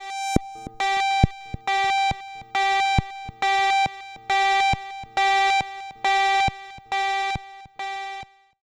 58-PULSE  -L.wav